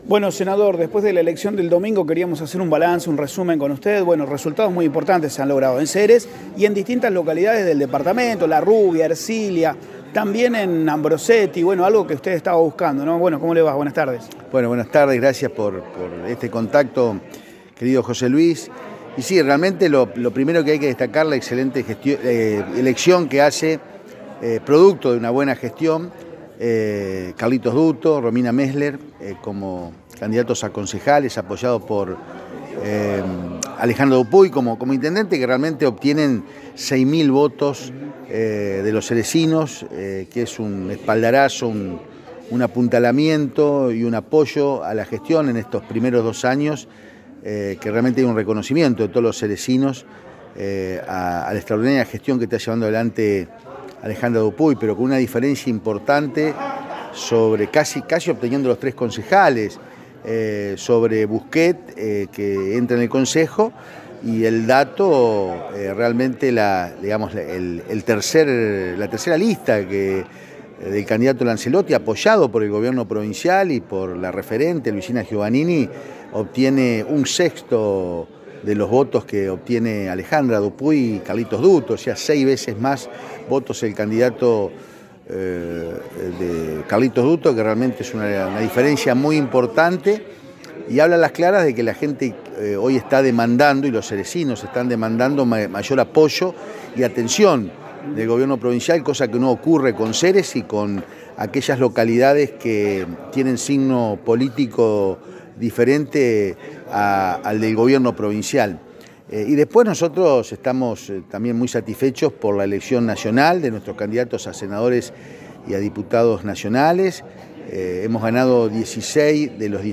En su visita a la ciudad de Ceres, el Senador Felipe Michlig hizo un balance de las elecciones del último domingo donde repasó el resultado electoral en distintas localidades del departamento.